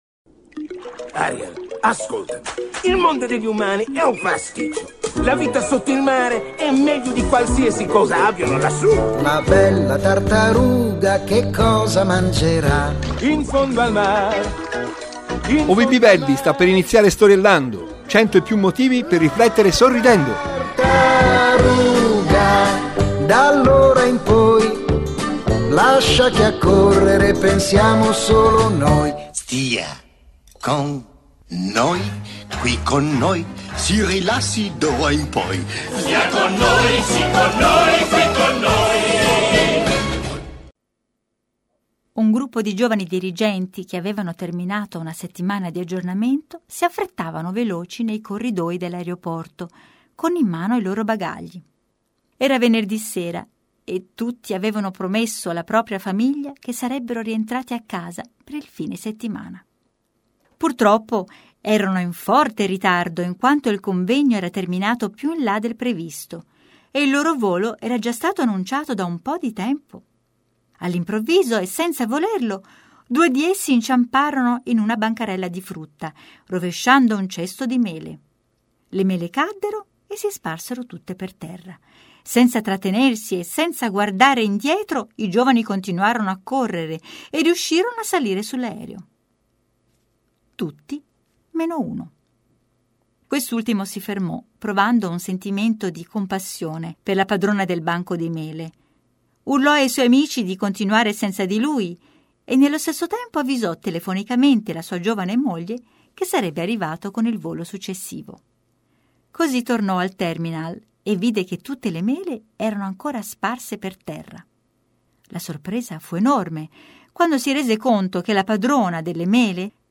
Un racconto di Giovanni Negro, per… riflettere sorridendo!